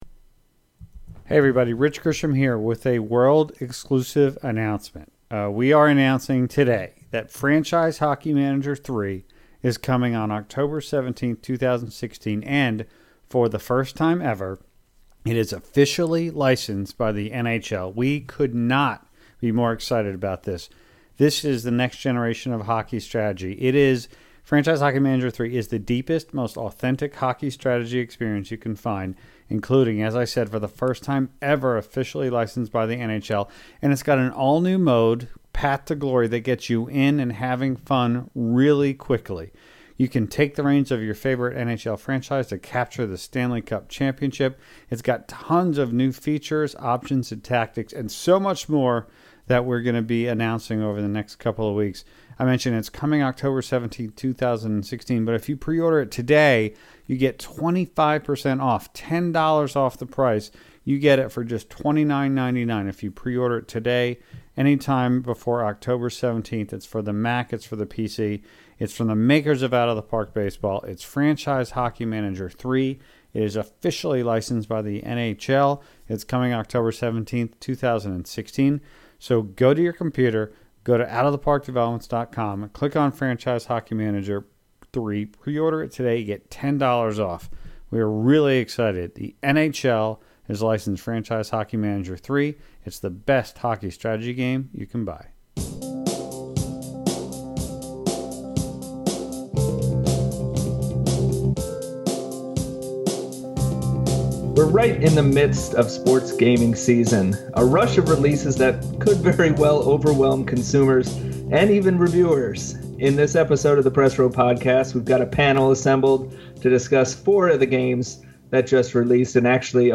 An unprecedented number of sports game releases within a compressed window of just a few weeks has left both consumers and reviewers struggling to find the time and/or money for all of them. The panel in this episode of the Press Row Podcast discusses at length four of the latest games to arrive on the market - NHL 17, NBA 2K17, Pro Evolution Soccer 2017, and Forza Horizon 3.